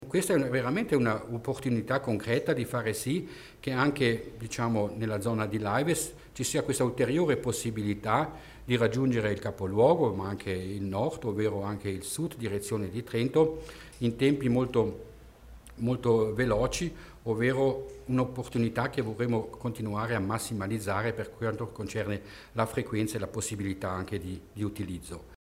L'Assessore Mussner spiega come sarà la nuova stazione di San Giacomo